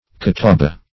Catawba \Ca*taw"ba\, n.